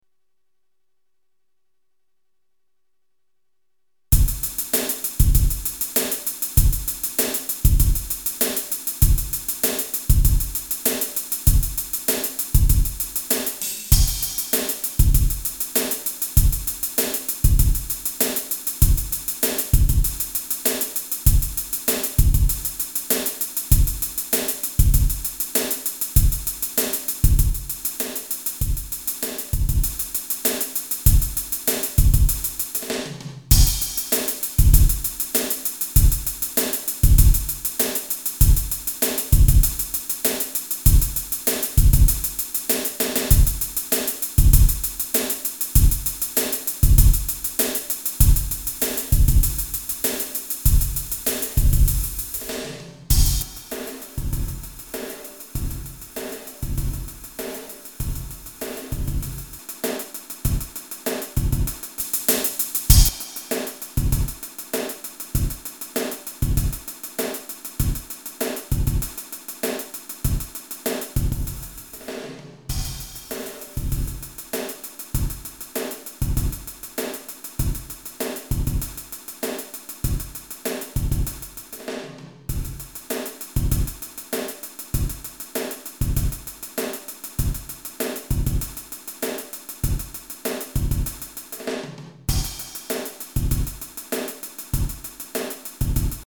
I use to love the sound of a gated reverb, so I tried to make on from an existing impulse, to try out a gated impulse reverb, here being tried with the hydrogen drumcomputer:
I used an extra non-impulse, feedback containing reverb to make the sound less dull, and indeed the gated reverb effect shines somewhat like it should.
gatedrev.mp3